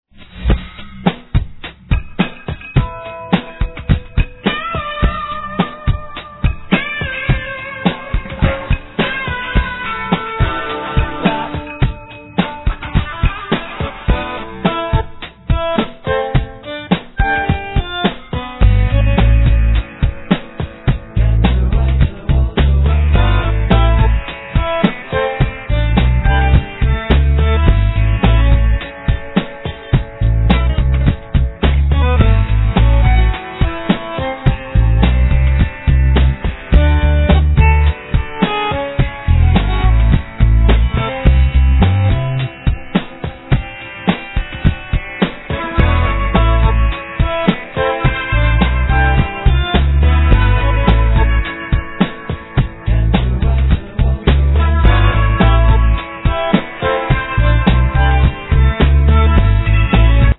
Bandoneon, Marimba, TR-808, Bongo, Drums
Bass
Synthesizer
Trumpet
Vocals